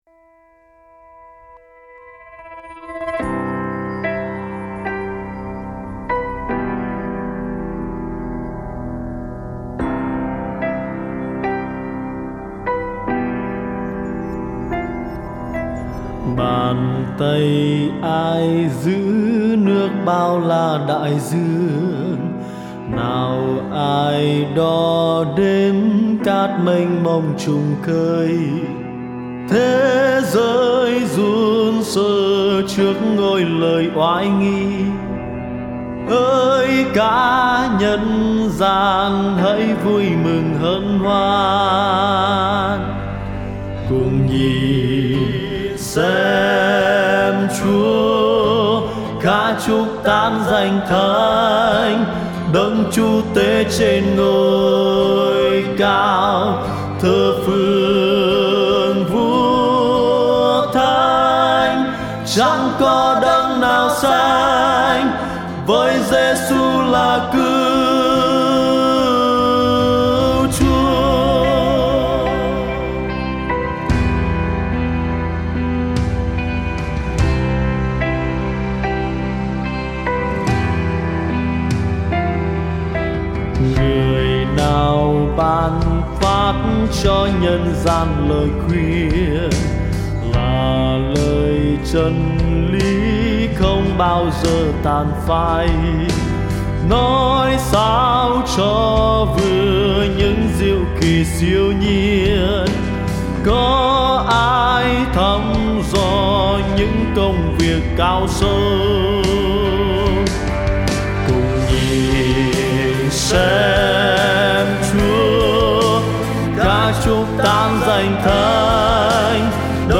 Nhạc Thánh